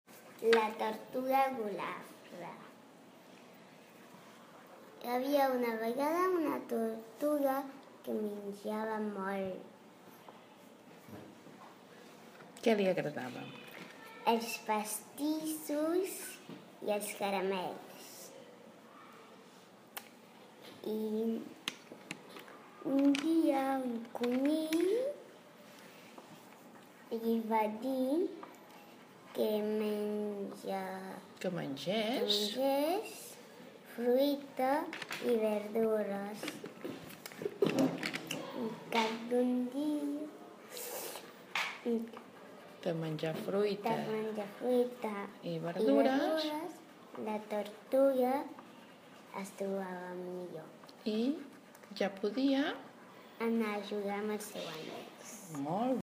ens llegeix el conte.